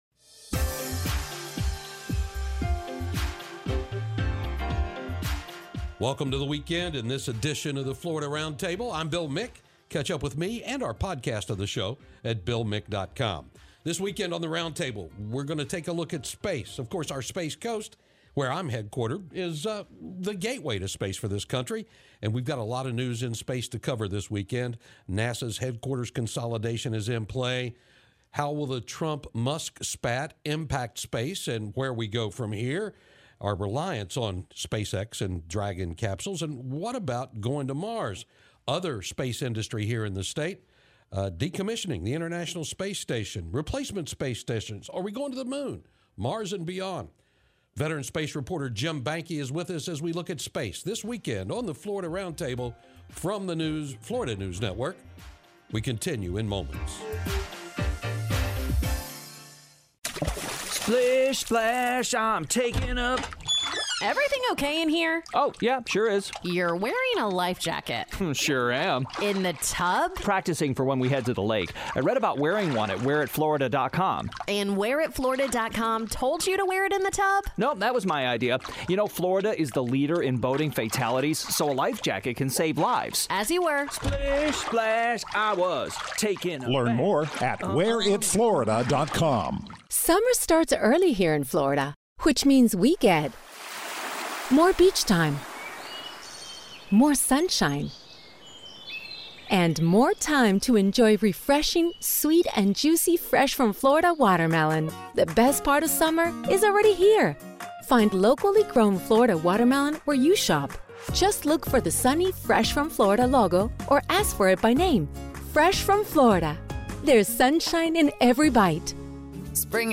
FNN's Florida Roundtable is a weekly, one-hour news and public affairs program that focuses on news and issues of Florida.